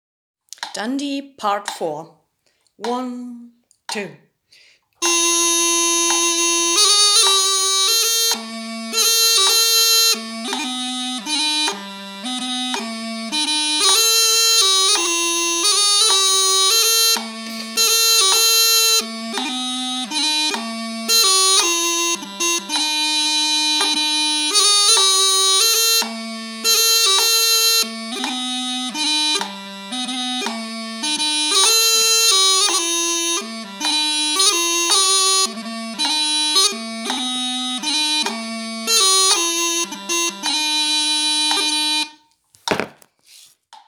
Bagpipe - Caverhill Guardians